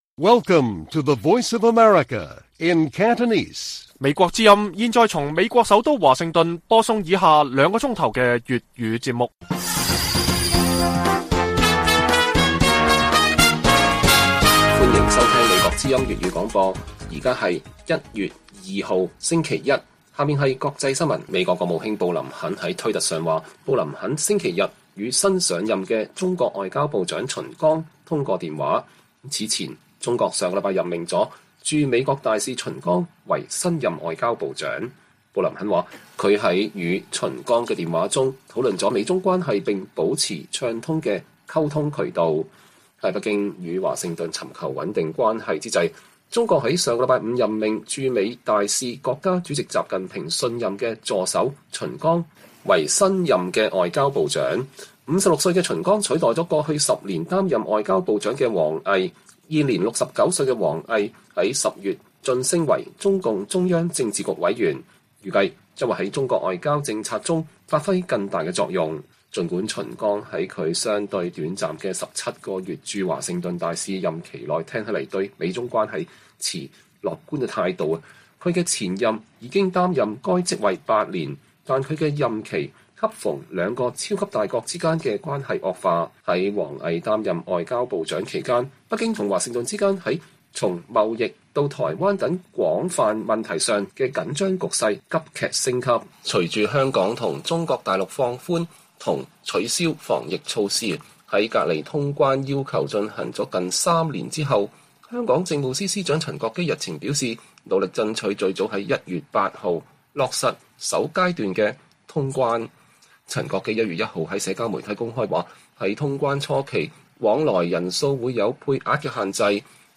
粵語新聞 晚上9-10點: 布林肯與秦剛通話討論美中關係